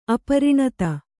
♪ apariṇata